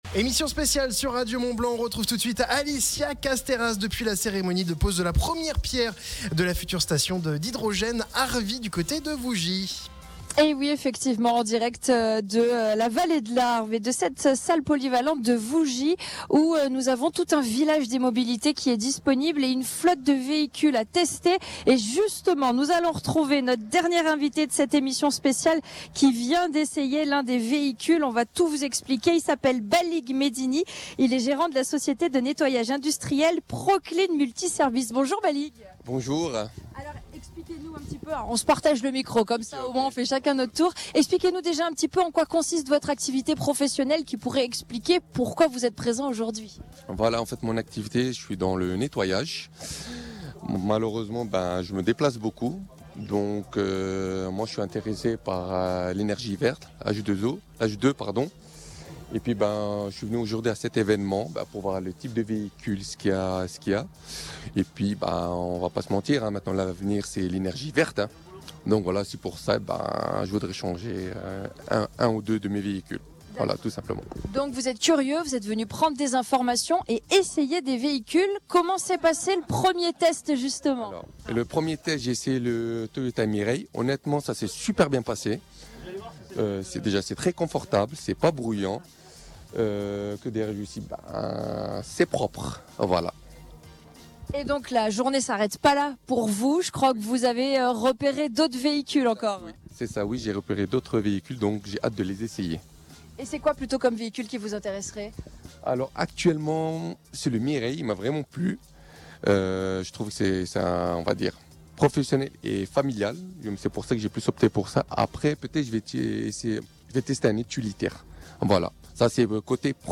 Ce jeudi 27 juin, Radio Mont Blanc était en direct de Vougy pour une émission spéciale à l’occasion de la pose de la première pierre de la future station multi-énergies Arv’Hy.